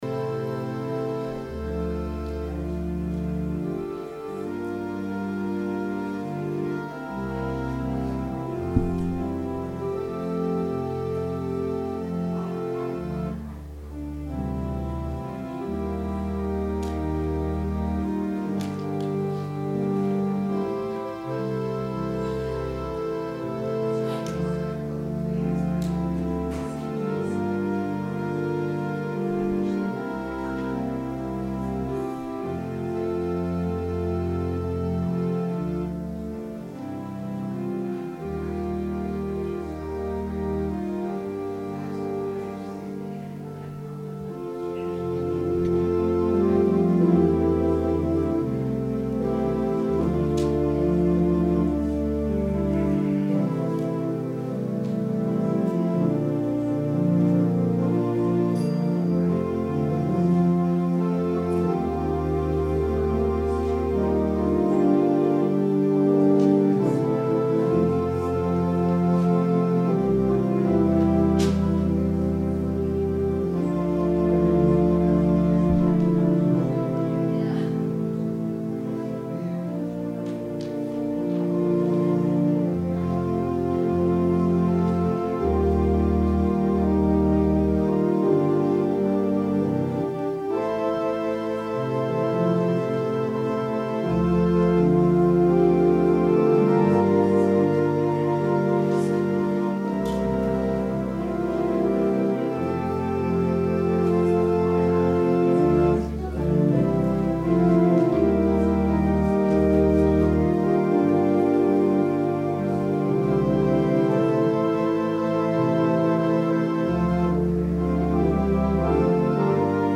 Sermon – November 4, 2018